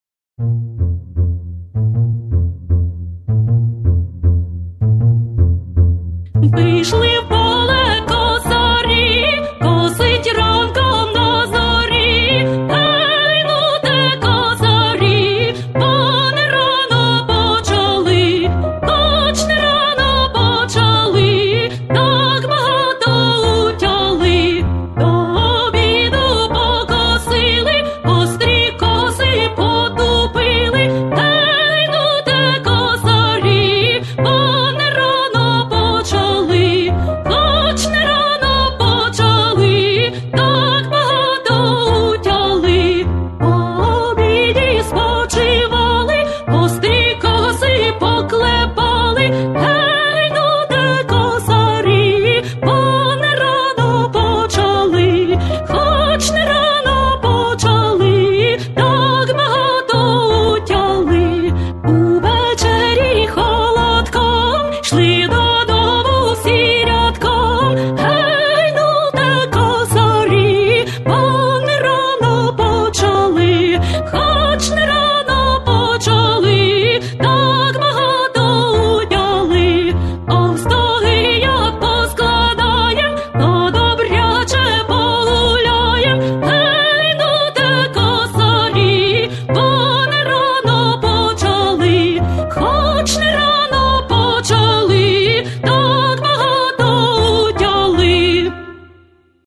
Рух косами в пісні «Вийшли в поле косарі» підкреслюють вигуки та акценти.
Українська народна пісня «Вийшли в поле косарі»